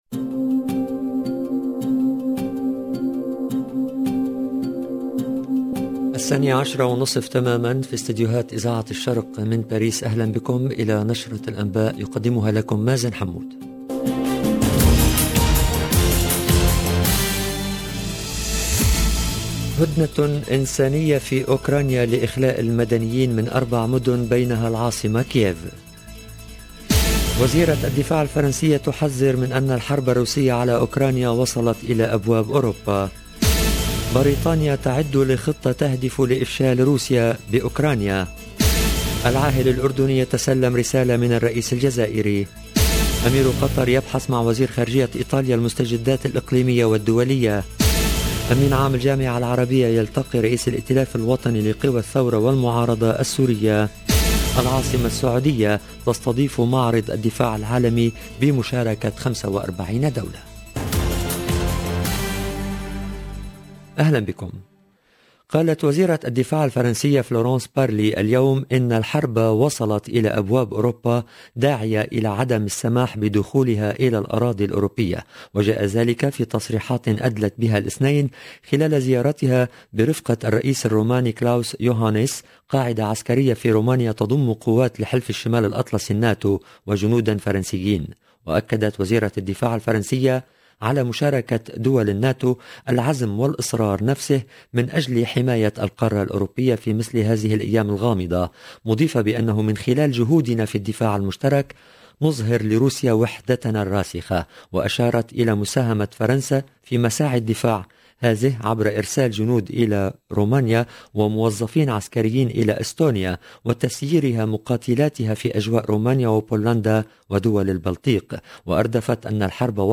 LE JOURNAL DE MIDI 30 EN LANGUE ARABE DU 7/03/22